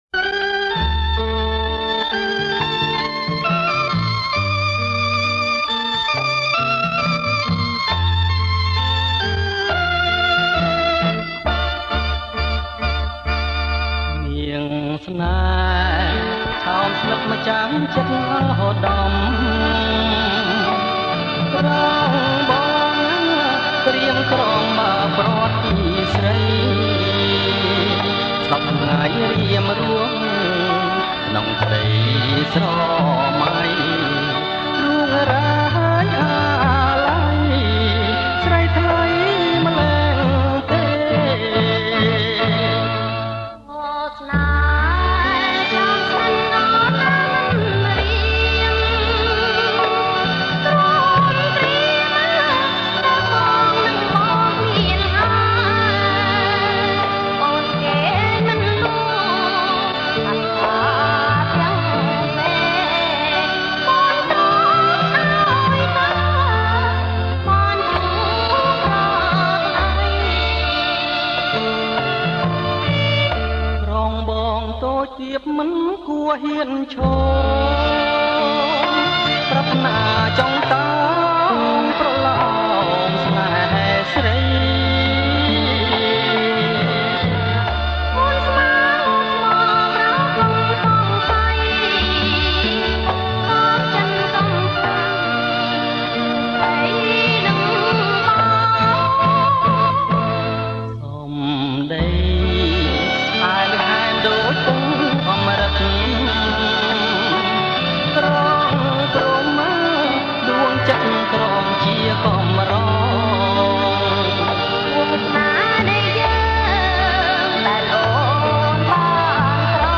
• ប្រគំជាចង្វាក់ Bolero Folk